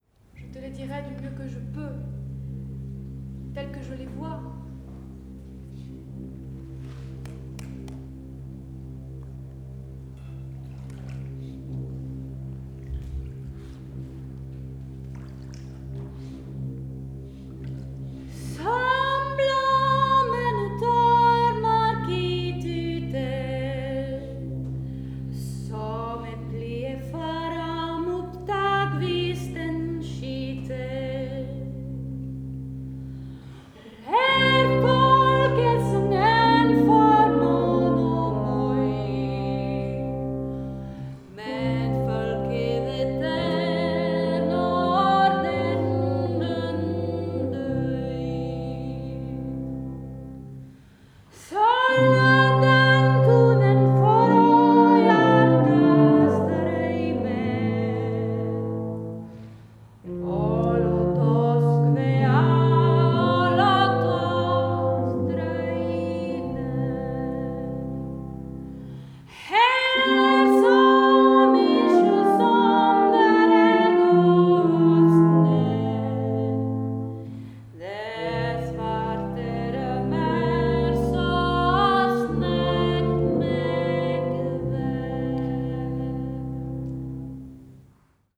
tuba
cor